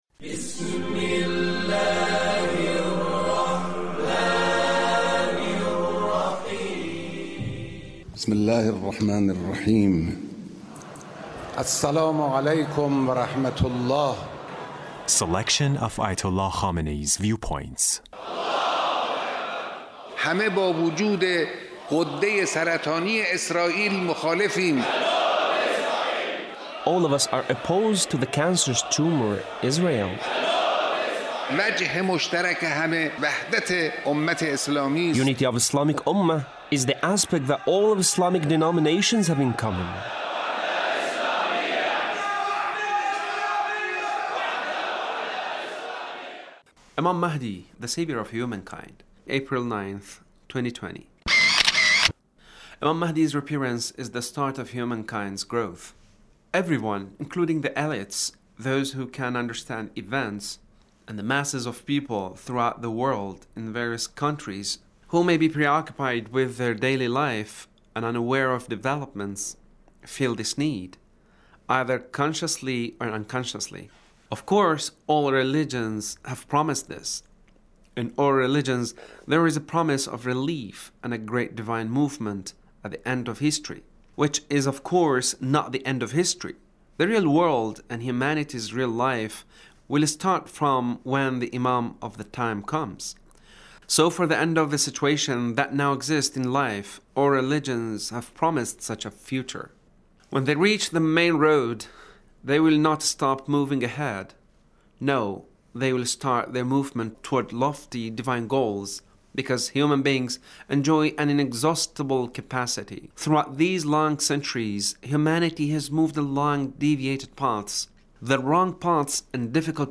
Leader's Speech on Graduation ceremony of Imam Hassan Mojtaba University